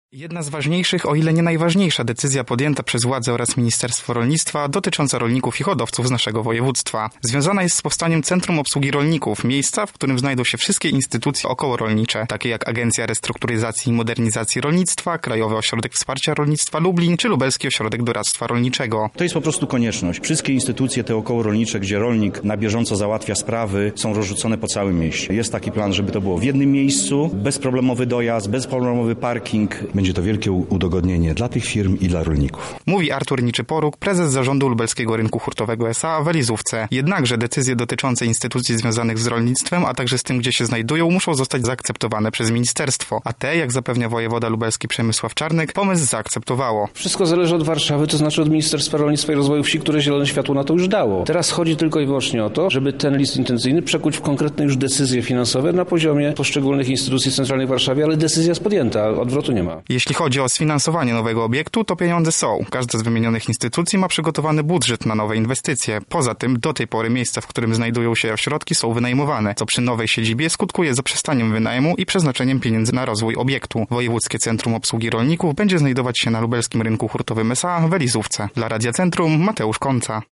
Powstanie nowy obiekt, który maksymalnie ułatwi załatwianie wszelkich spraw przez osoby związane z rolnictwem i hodowlą. Więcej szczegółów znajduje się w materiale naszego reportera: